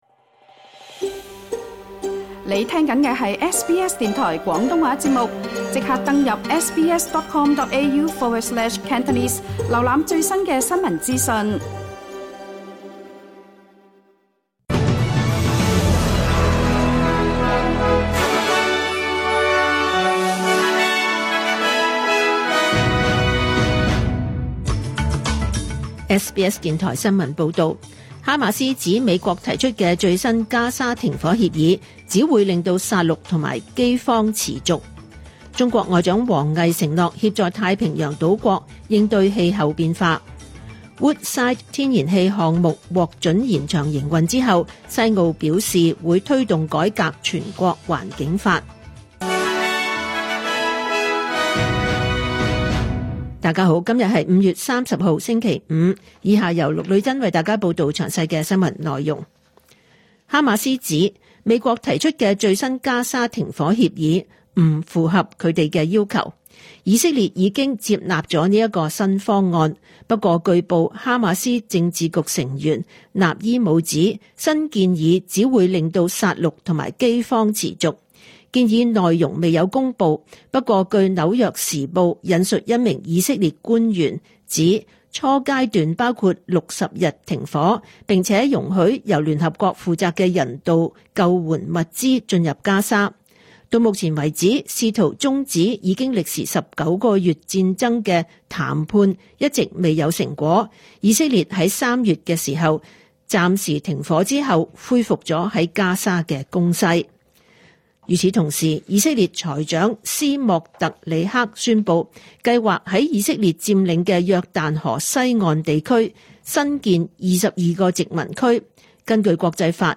2025 年 5 月 30 日 SBS 廣東話節目詳盡早晨新聞報道。